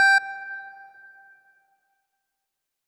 G2.wav